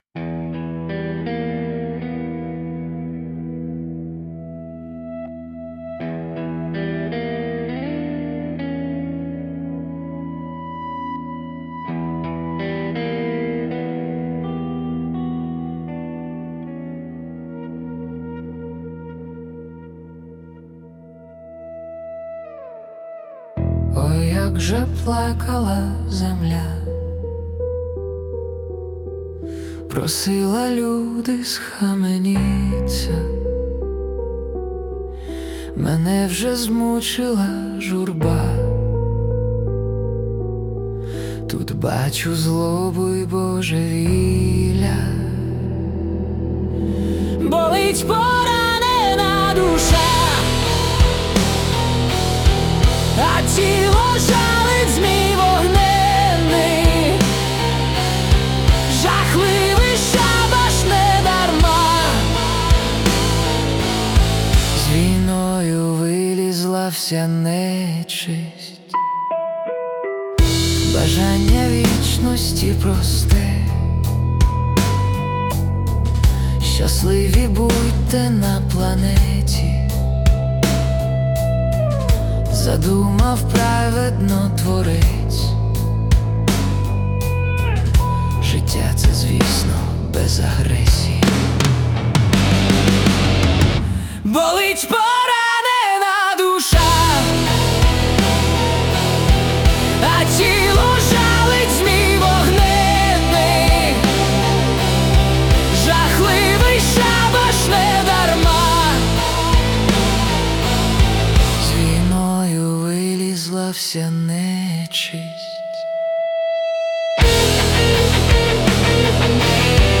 Музична композиція створена за допомогою SUNO AI
СТИЛЬОВІ ЖАНРИ: Ліричний